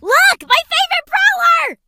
colette_start_vo_02.ogg